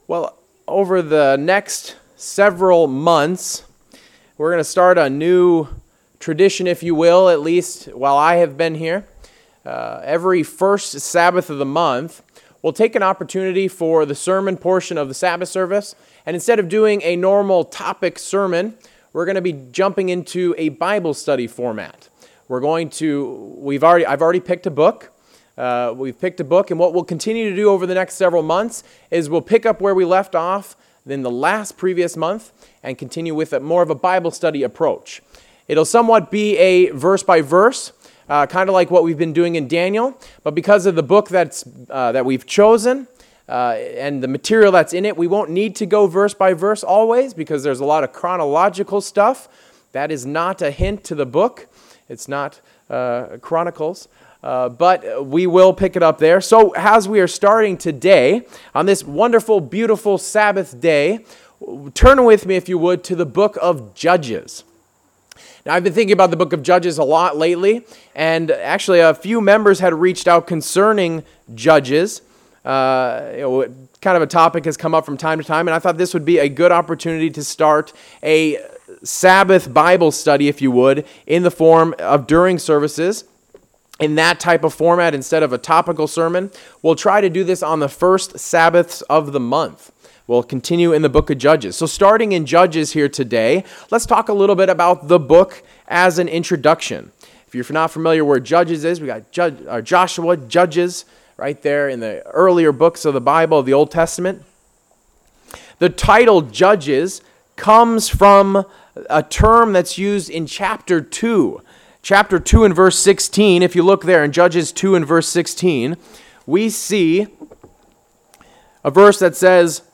Sermons
Given in Bangor, ME Saratoga Springs, NY Southern New Hampshire Worcester, MA